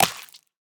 1.21.5 / assets / minecraft / sounds / mob / dolphin / eat3.ogg
eat3.ogg